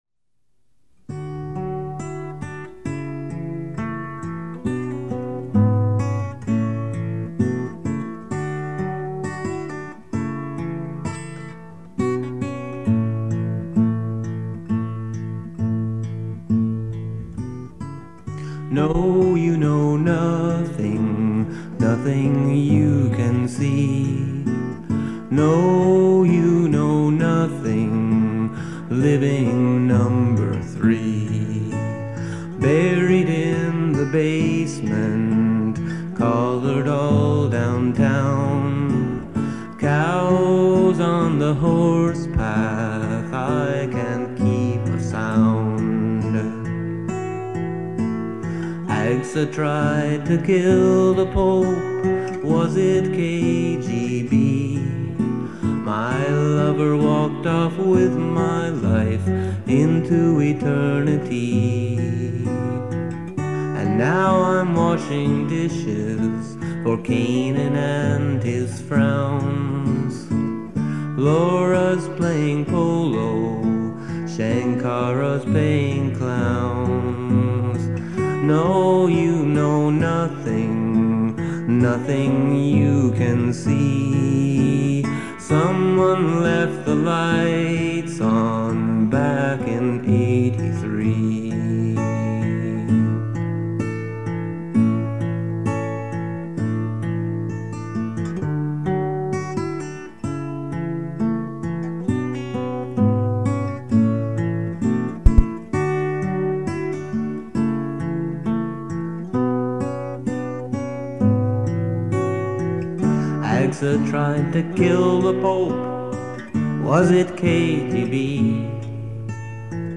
Know-You-Know-Nothing-Studio-Version.mp3